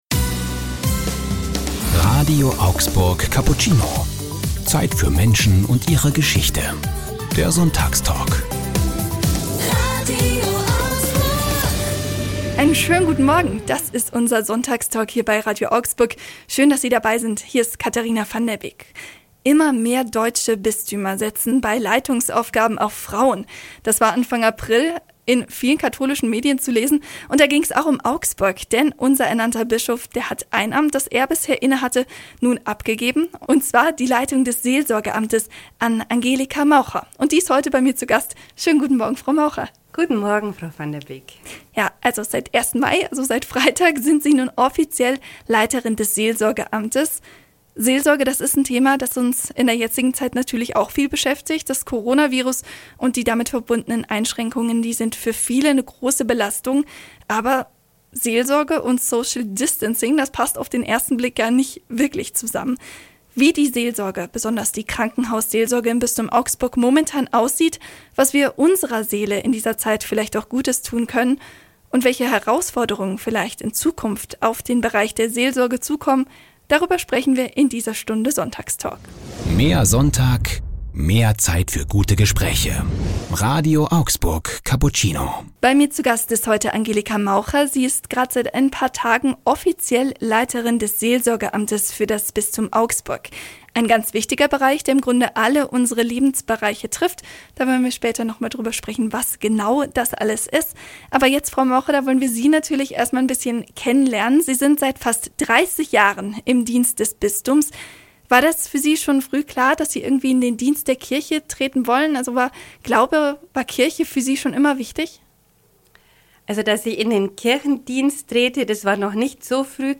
Sonntagstalk: Seelsorge und Social Distancing ~ RADIO AUGSBURG Cappuccino Podcast